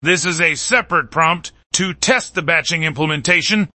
Generation Samples
• ✅ Outputs match the quality of the original Chatterbox implementation.